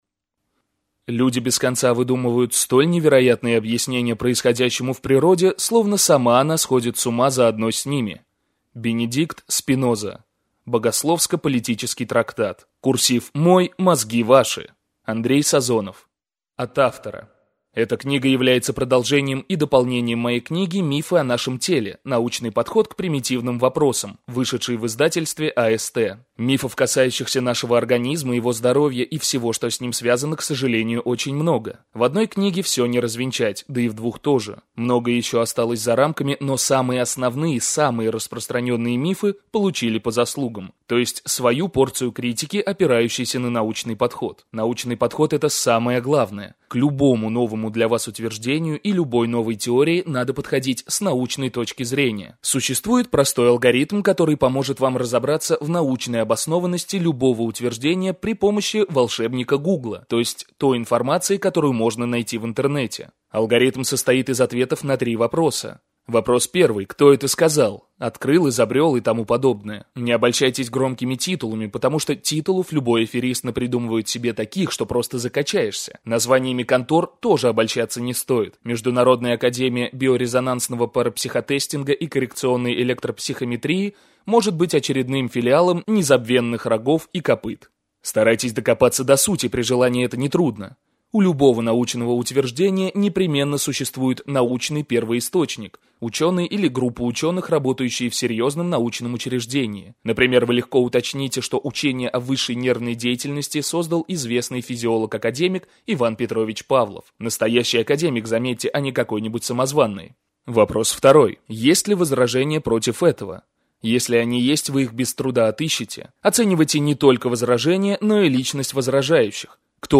Аудиокнига [Не]правда о нашем теле.